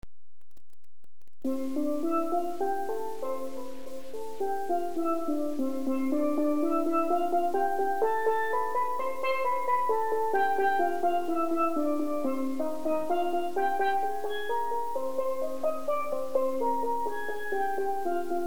C Lead Pan
(Trinidad-Low Tenor)
This C Lead has 29 notes and a six inch skirt.
This pan has the highest pitch and plays the melody in the steelband or solo.
Range = 1st Violin = Soprano
c_lead.wav